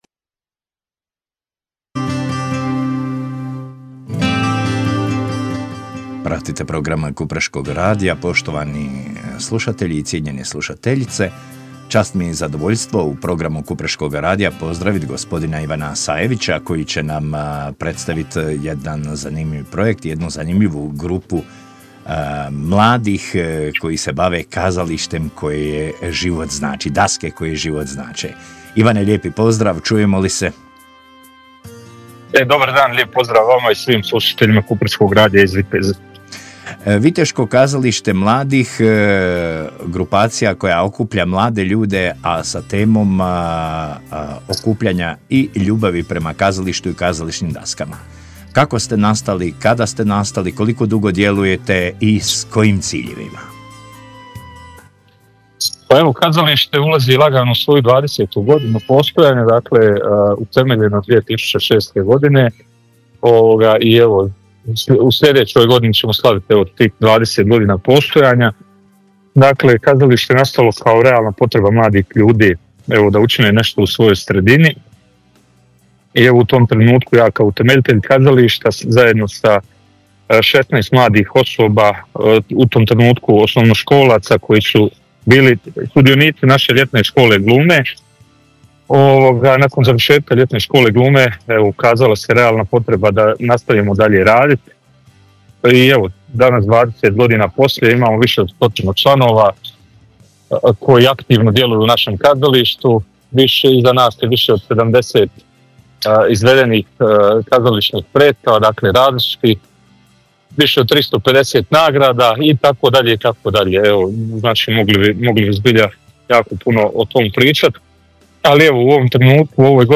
Najava i razgovor: Pidžama za šestero u Hrvatskom domu kulture